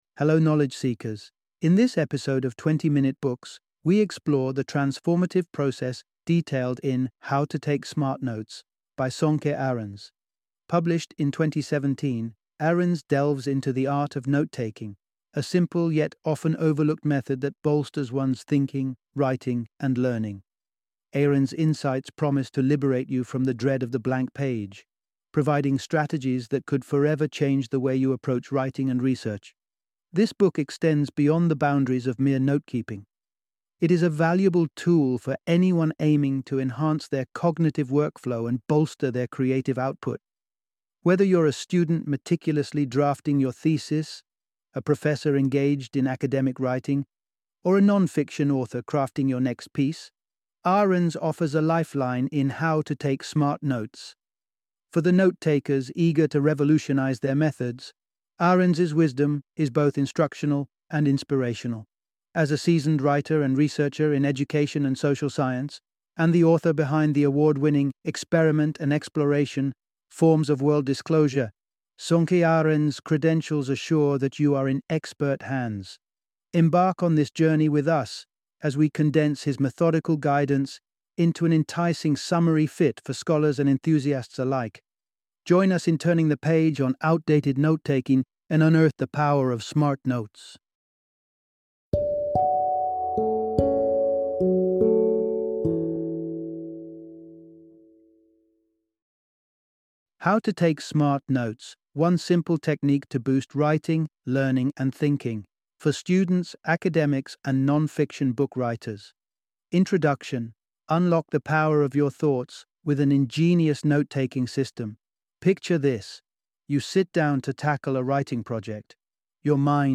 How to Take Smart Notes - Audiobook Summary